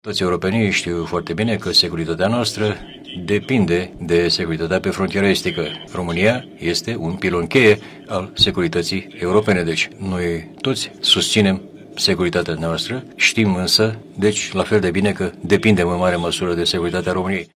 Discursul lui Antonio Costa a fost tradus de un reprezentant al Administrației Prezidențiale.